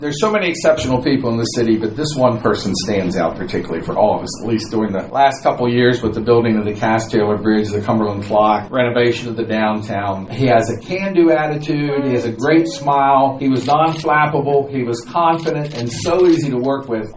Let’s Beautify Cumberland presented their annual Blue Ribbon Awards during Tuesday night’s Cumberland City Council meeting.